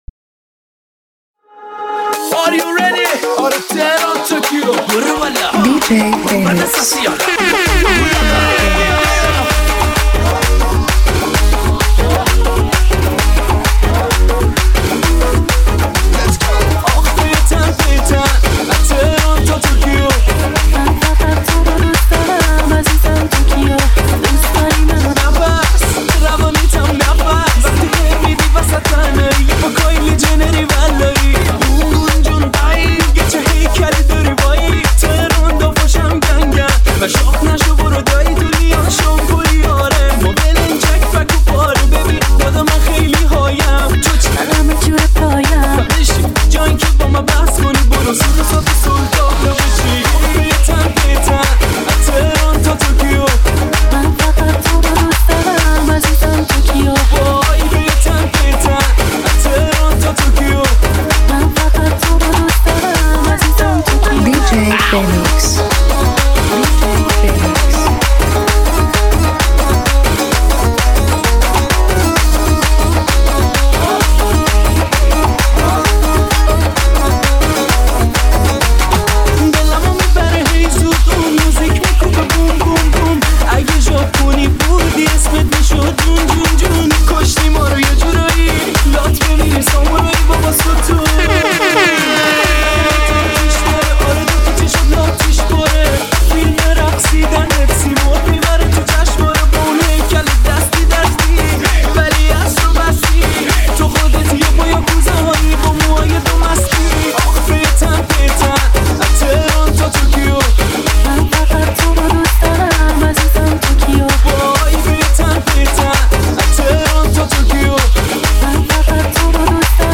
دانلود ریمیکس شاد و پر انرژی آهنگ
با ضرب‌آهنگ‌های کوبنده و فضاسازی منحصربه‌فرد